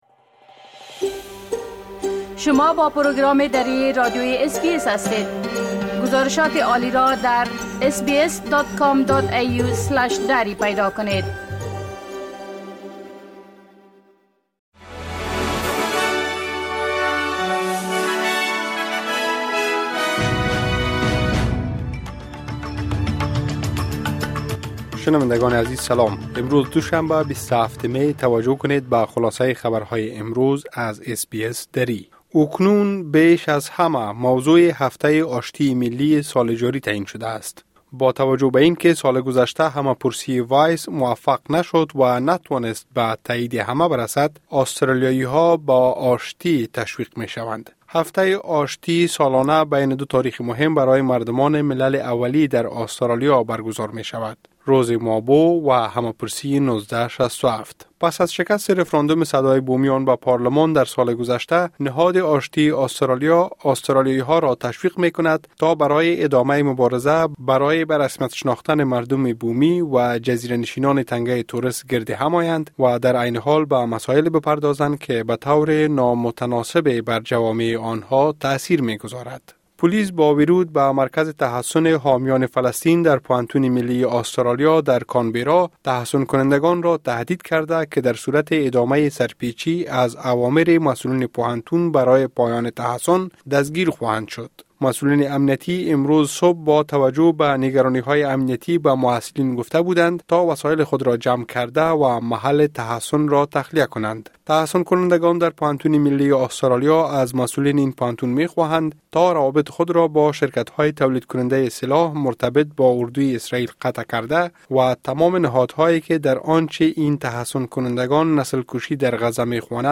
خلاصۀ مهمترين اخبار روز از بخش درى راديوى اس بى اس|۲۷ می ۲۰۲۴